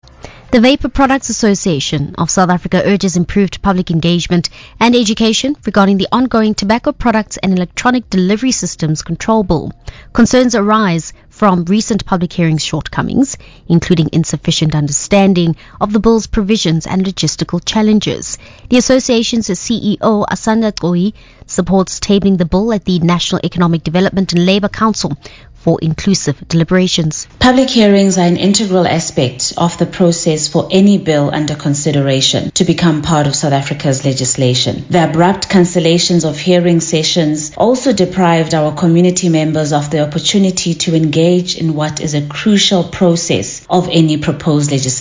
RADIO: Voice of Wits - Interview on the proposed tobacco bill